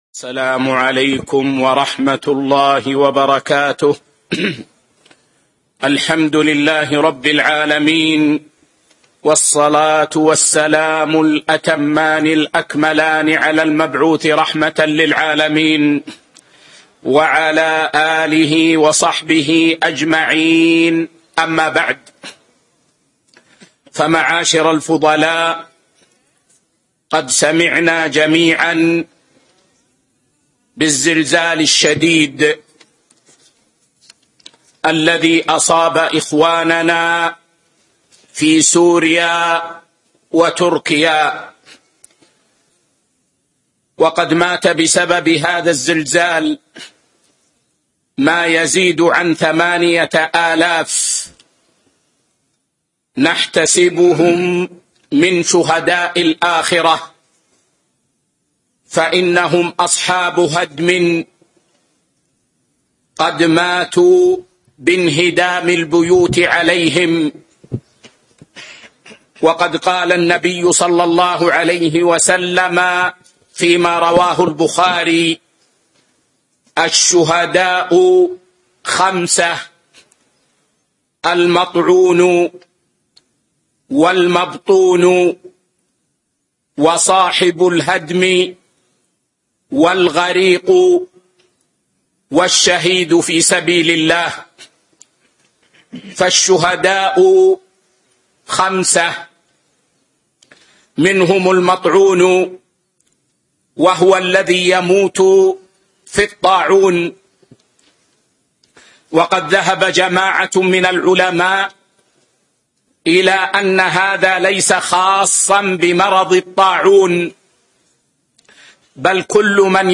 كلمة - حول الزلزال الذي أصاب إخواننا في سوريا وتركيا